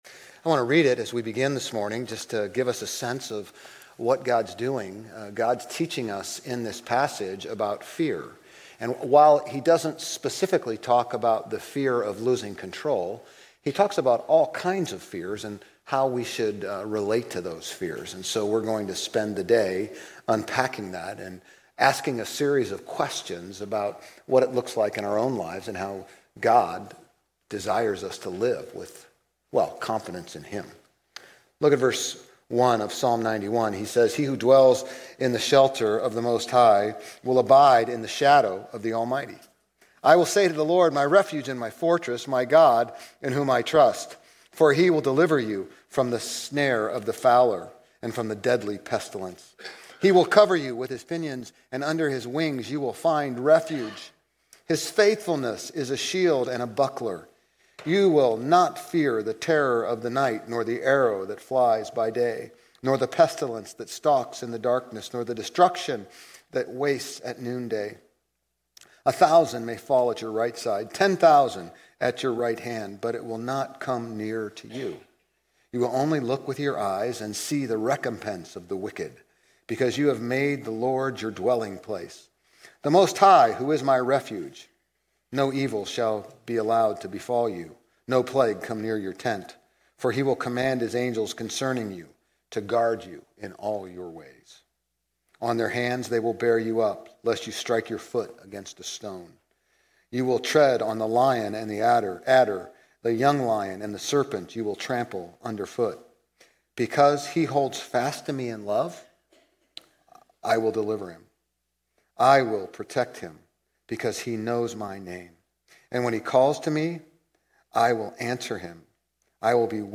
Grace Community Church Old Jacksonville Campus Sermons 5_11 Old Jacksonville Campus May 11 2025 | 00:34:22 1x 00:00 / 00:34:22 Subscribe Share RSS Feed Share Link Embed